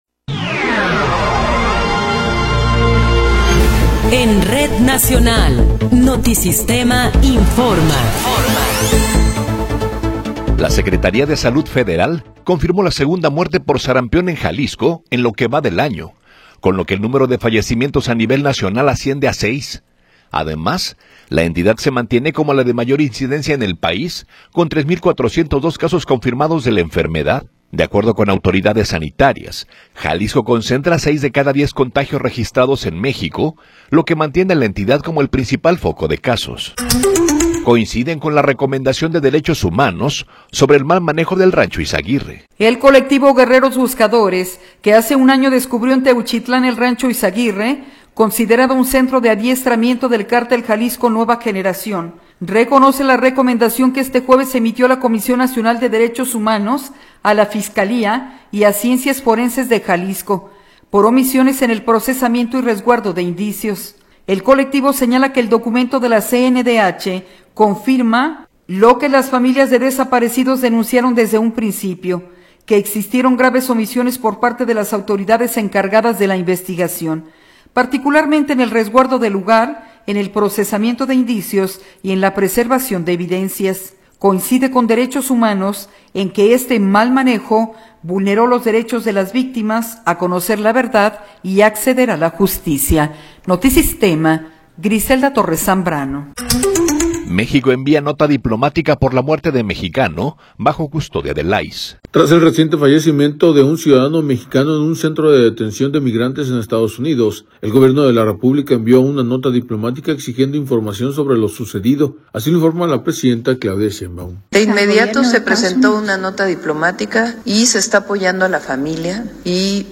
Noticiero 13 hrs. – 5 de Marzo de 2026